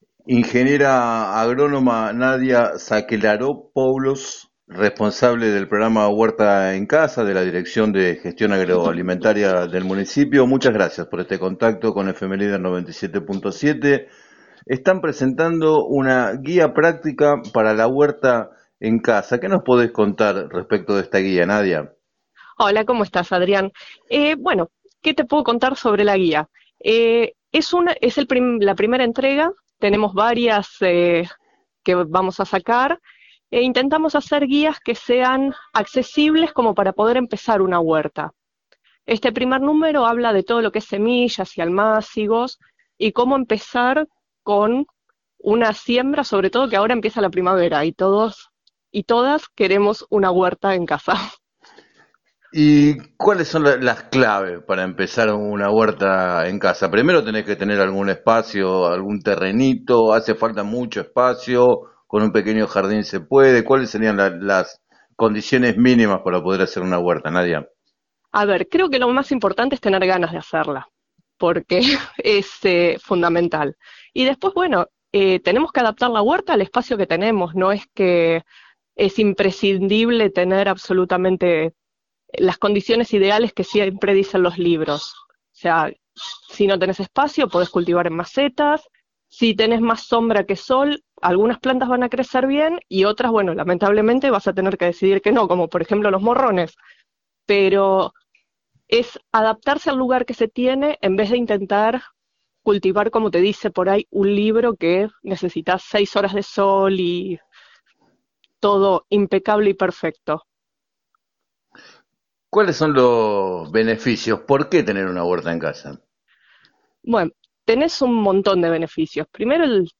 En declaraciones al programa “7 a 9” de FM Líder 97.7